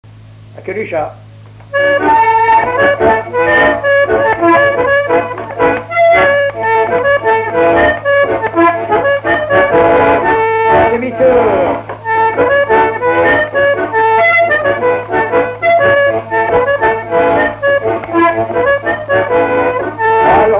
Mémoires et Patrimoines vivants - RaddO est une base de données d'archives iconographiques et sonores.
Résumé instrumental
danse : quadrille : queue du chat
Pièce musicale inédite